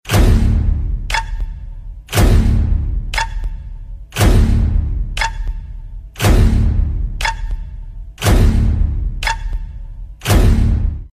Mobile Clicks.